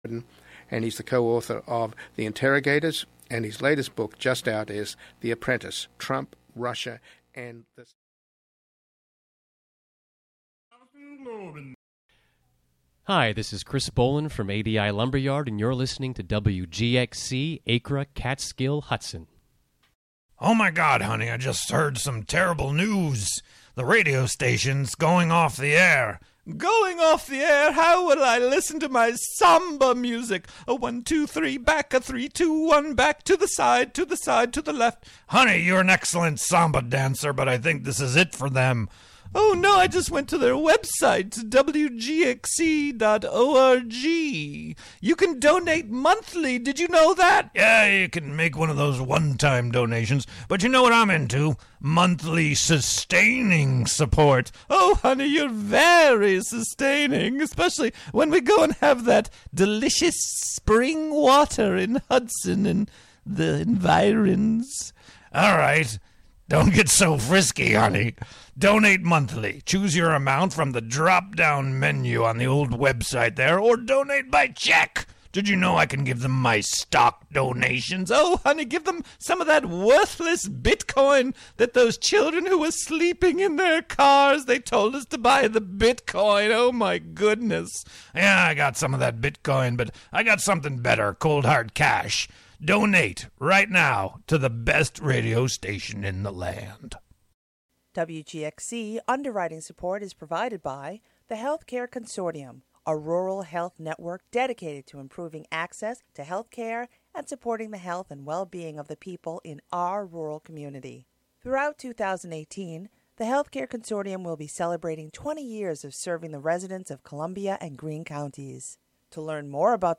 "All Together Now!" is a daily news show brought to you by WGXC-FM in Greene and Columbia counties. The show is a unique, community-based collaboration between listeners and programmers, both on-air and off. "All Together Now!" features local and regional news, weather updates, feature segments, and newsmaker interviews.